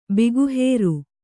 ♪ biguhēru